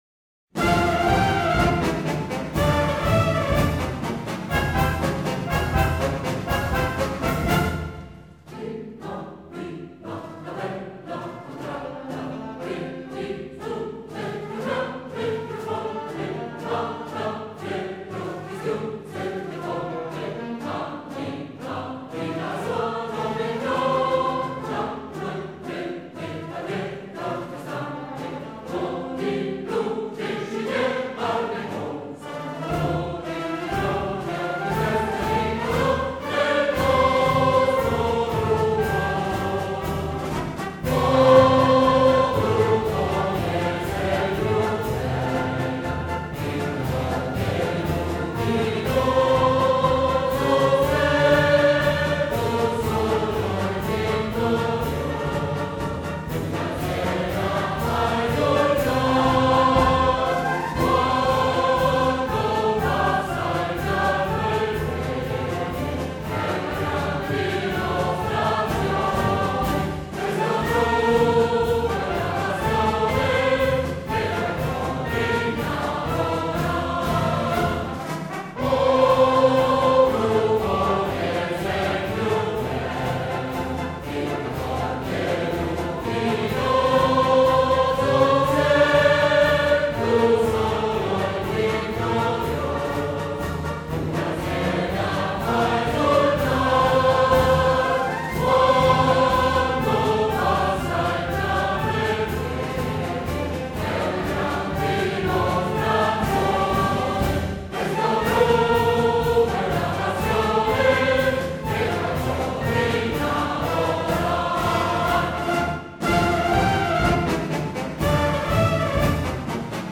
Versi di Sergio Ghiselli, musica del Maestro Mario Caciagli – E’ un inno in 2/4, “marciabile”, in cui predomina una parte contrappuntata appoggiata sulla componente baritonale e sui bassi.